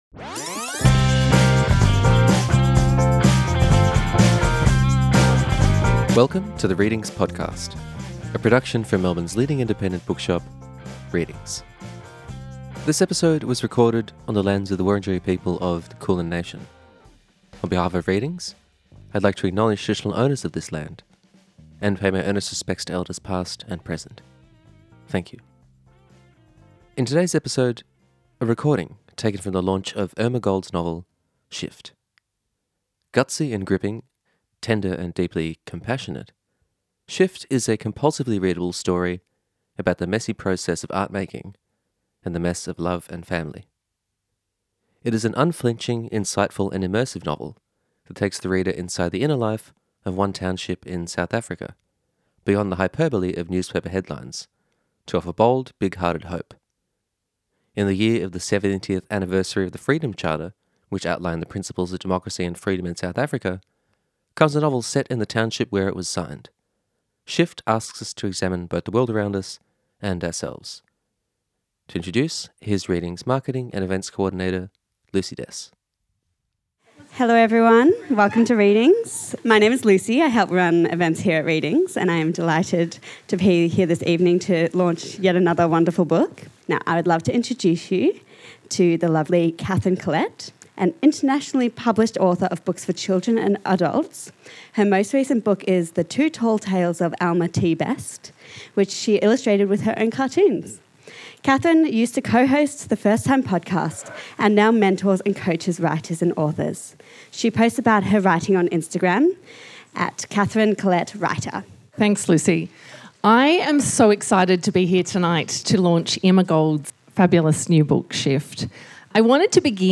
at Readings, Carlton